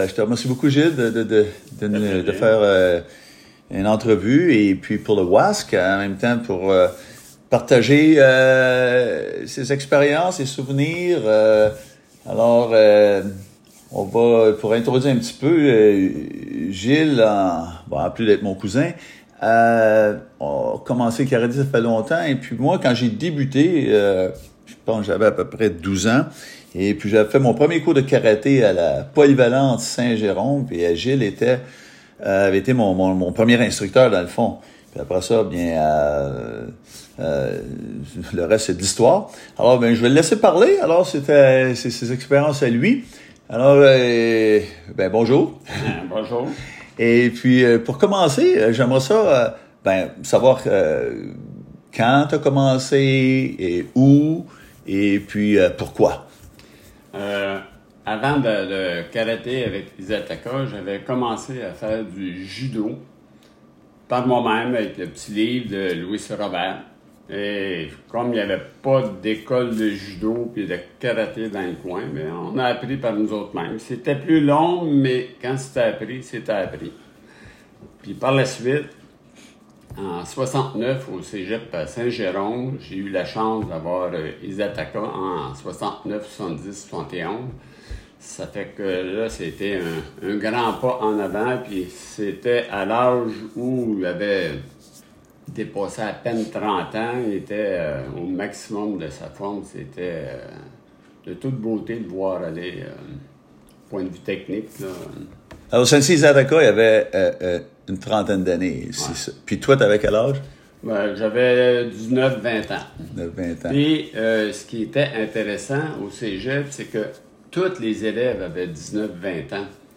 Interviews
Interviews with martial artists from around the world